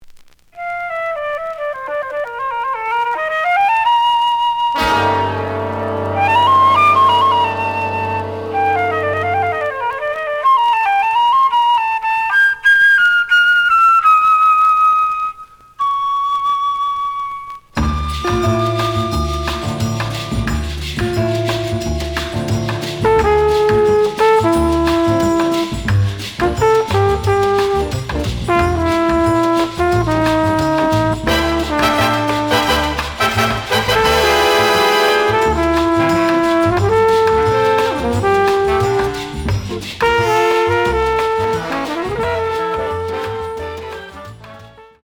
The audio sample is recorded from the actual item.
●Genre: Bop